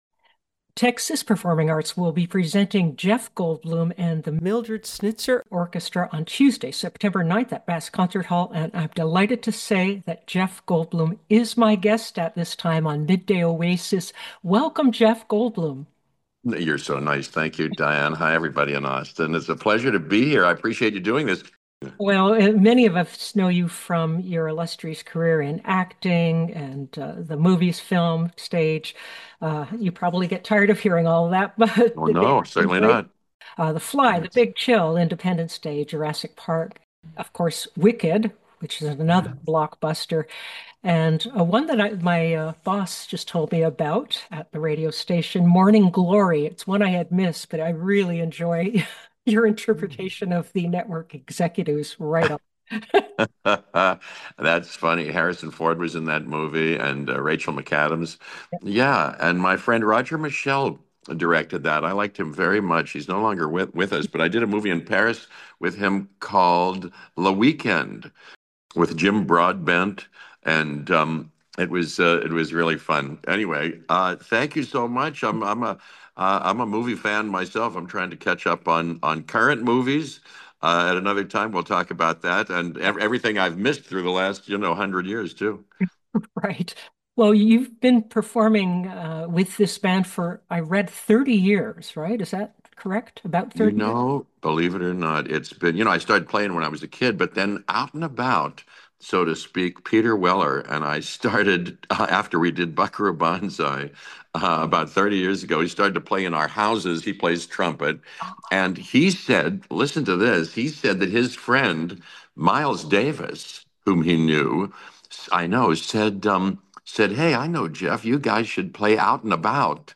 Jeff Goldblum Interview
Jeff_Goldblum_Full_Interview.mp3